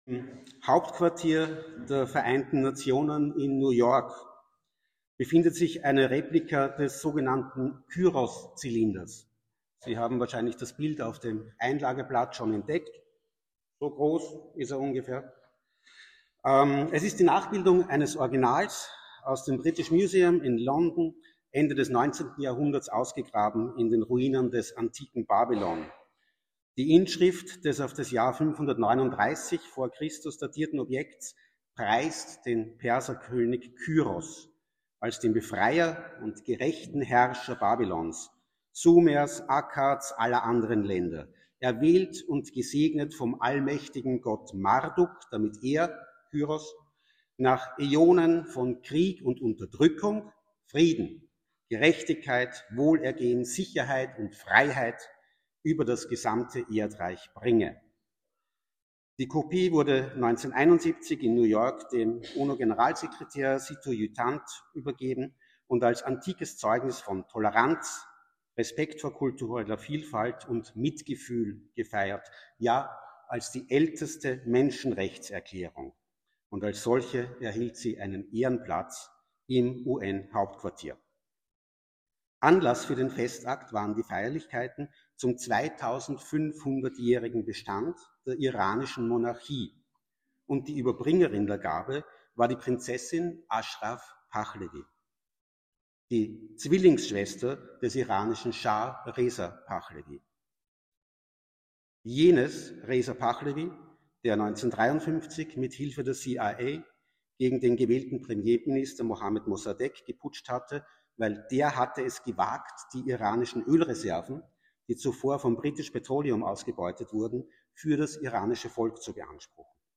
Worte zur Schrift